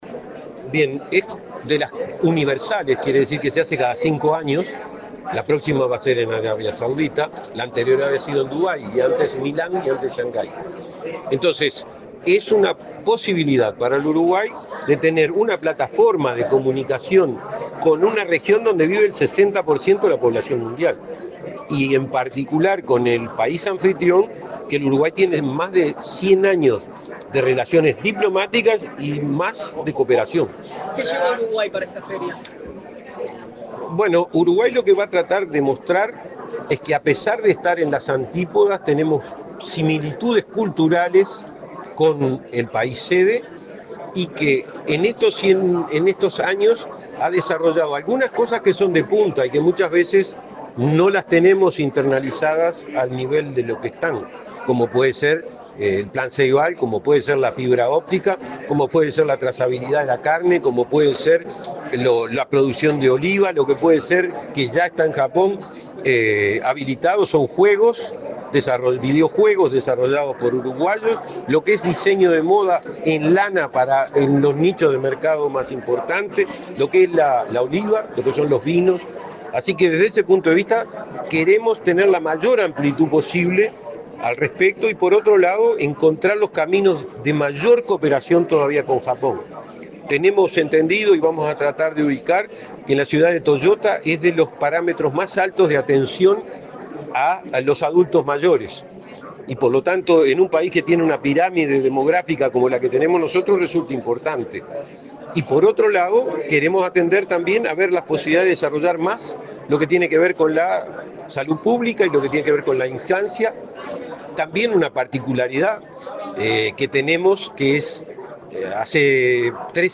El comisario de Uruguay en la Expo Osaka 2025, Benjamín Liberoff, dialogó con la prensa en la Torre Ejecutiva, luego de intervenir en la presentación